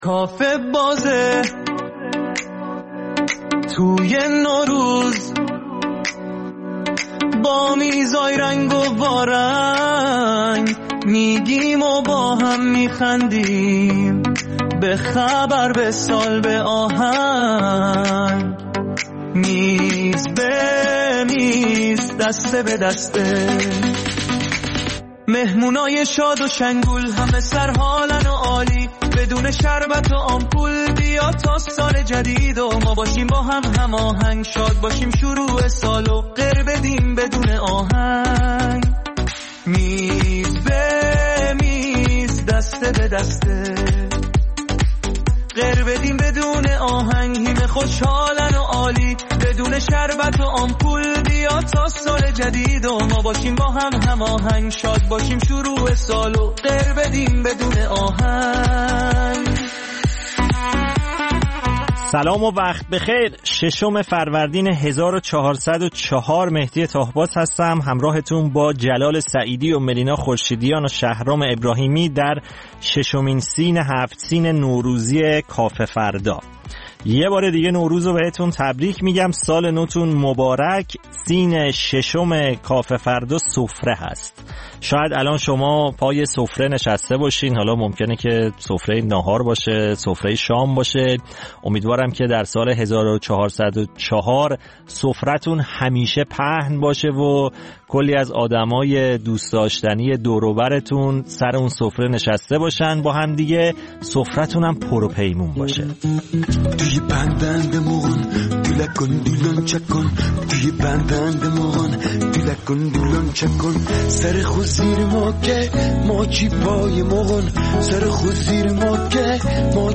در کافه فردای نوروز با سفره هفت‌سین خاص کافه‌فردایی‌ها شوخی می‌کنیم و در میز‌های مختلف و بخش‌های متفاوت نمایشی وجوه متفاوت نوروز و تعطیلات نوروزی را در کنار هم به مرور می‌نشینیم.